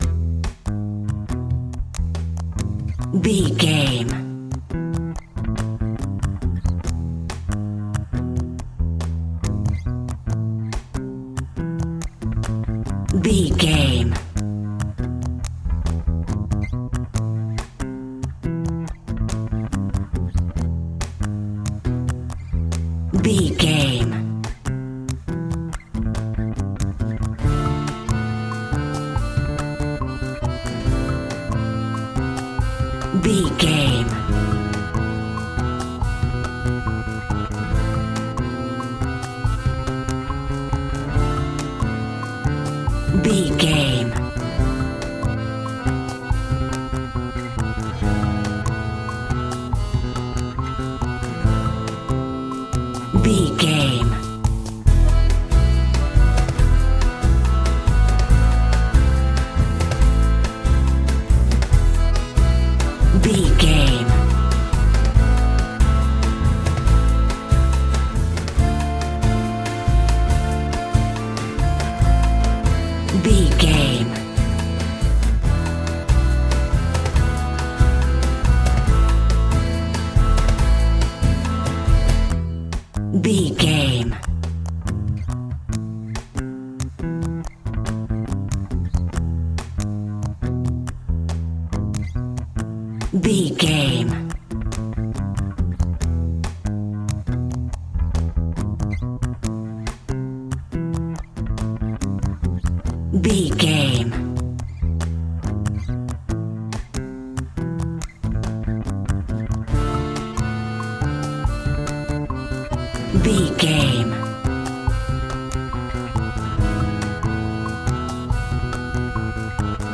Uplifting
Ionian/Major
maracas
percussion spanish guitar
latin guitar